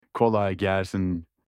تلفظ صحیح Kolay Gelsin